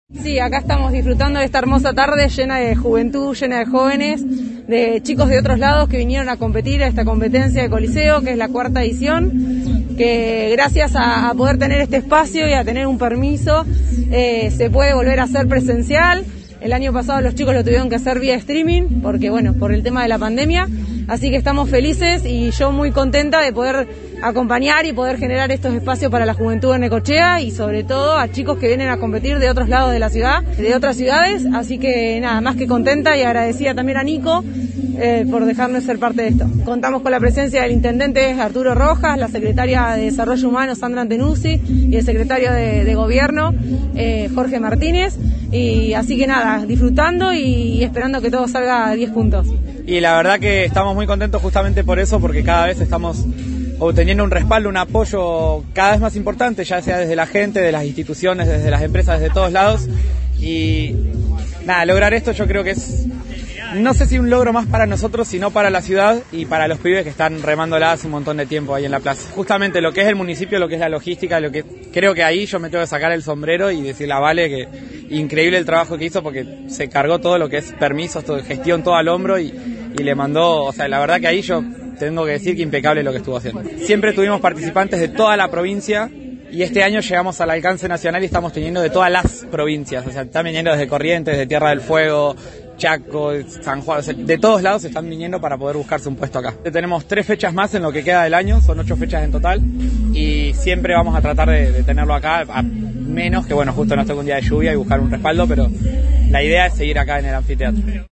En un alto del evento que volvió a ser presencial tras la peor parte de una pandemia que sin embargo aún persiste y, por tal caso, con los protocolos vigentes